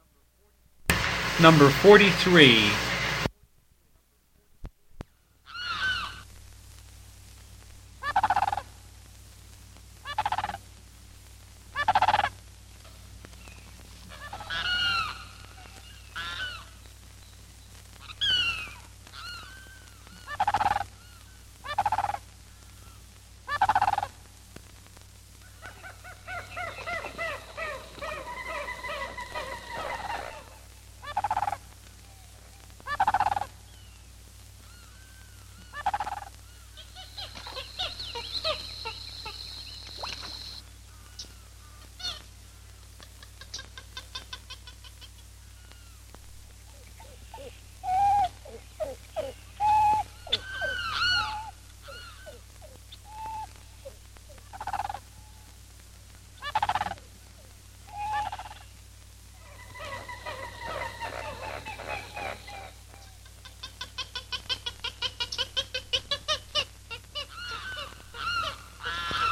复古动物声音 " G1233野生动物叫声
描述：高亢的尖叫声和树皮。难以区分的动物。猴子或鸟。 这些是20世纪30年代和20世纪30年代原始硝酸盐光学好莱坞声音效果的高质量副本。
我已将它们数字化以便保存，但它们尚未恢复并且有一些噪音。
声道立体声